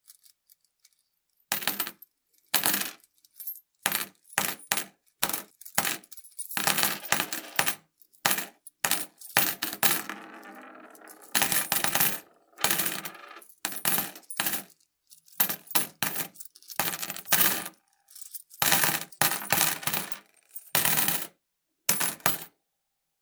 Monedas cayendo: Efectos de sonido negocios
¡El tintineo del dinero a tu alcance!
Este efecto de sonido ha sido grabado para capturar la nitidez del sonido de monedas al impactar una superficie, proporcionando un sonido claro y distintivo que se integrará perfectamente en tus proyectos.
Tipo: sound_effect
Monedas cayendo.mp3